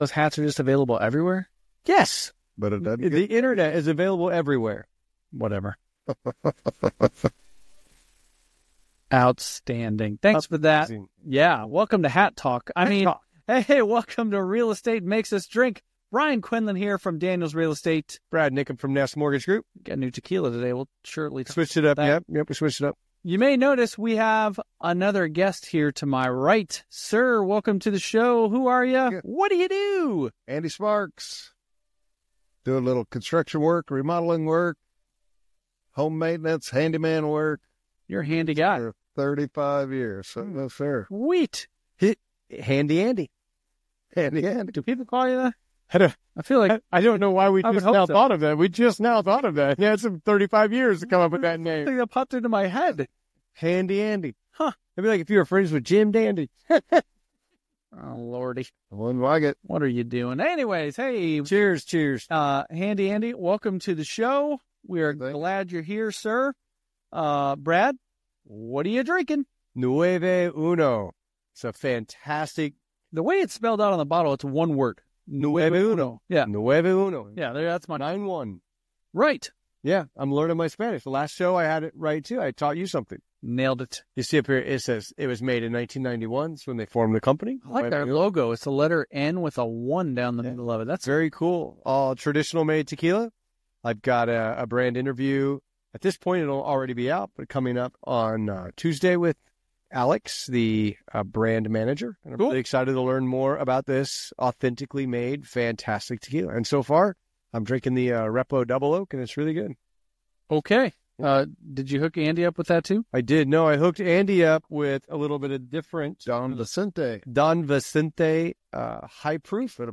Grab a drink, relax, and enjoy another fun and insightful conversation on Real Estate Makes Us Drink!